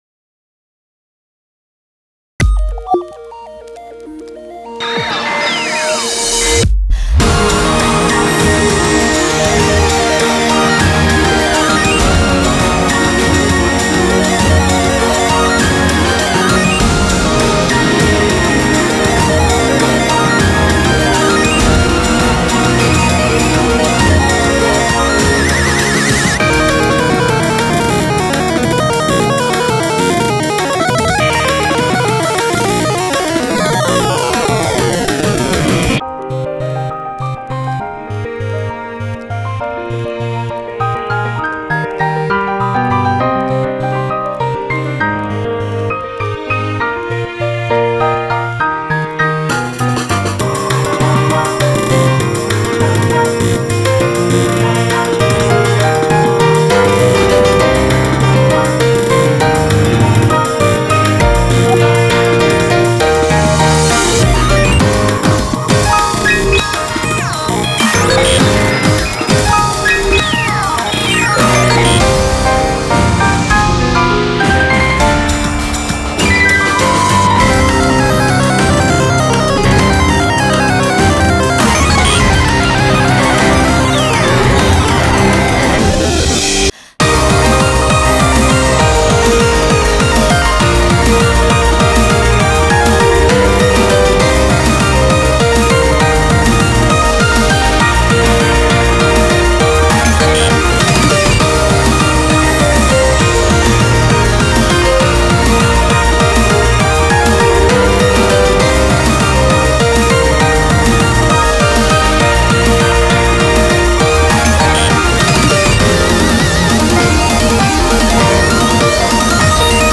BPM100-200
- Ending BPM change is different